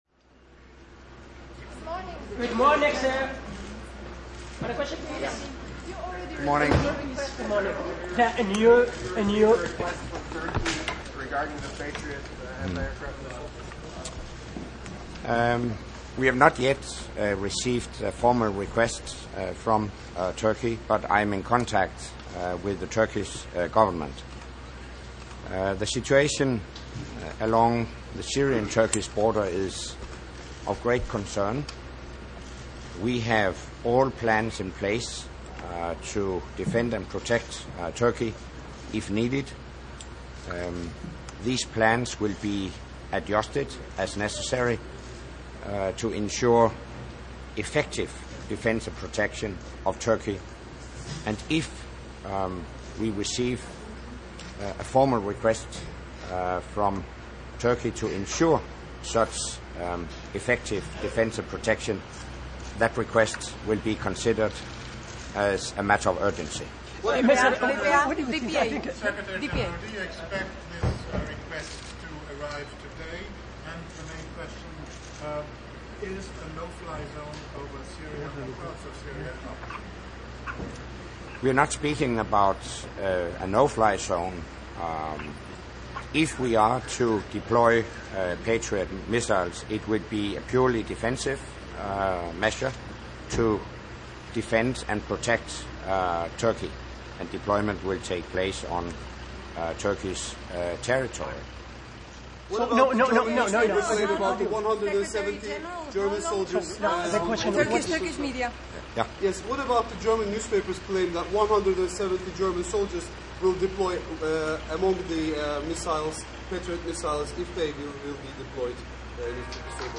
NATO Secretary General Anders Fogh Rasmussen answered questions on Turkey and the Middle East upon arriving to attend Monday’s European Union defence and foreign affairs ministerial.